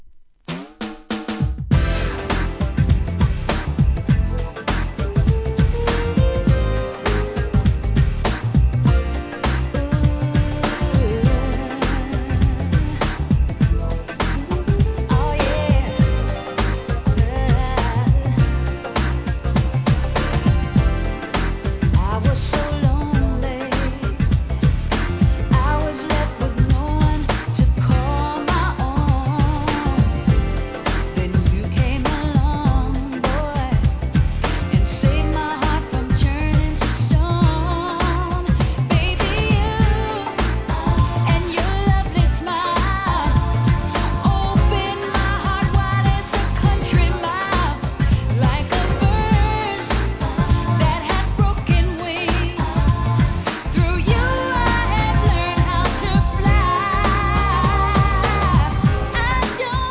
1. HIP HOP/R&B
ミディアムテンポの人気跳ねナンバーとポエトリーリーディングで聴かせる雰囲気抜群のナンバーをカップリング！！